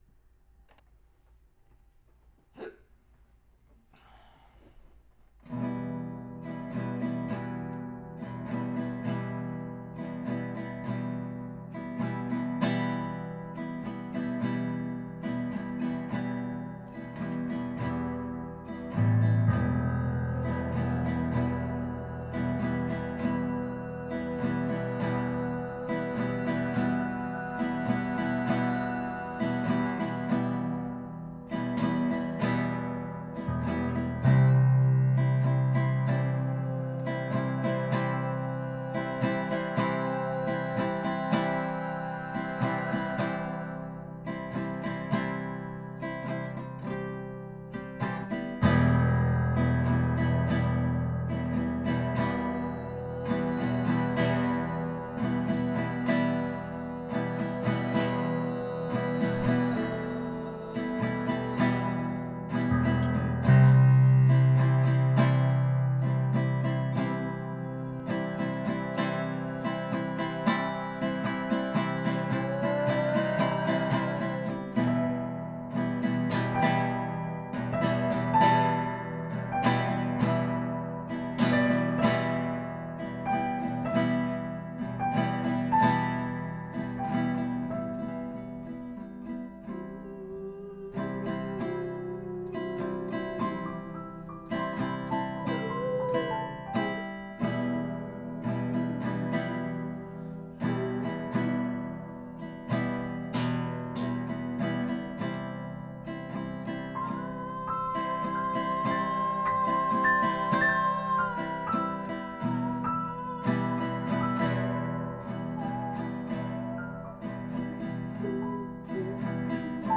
I made this "song" up as was playing and just went back and added stuff afterwards.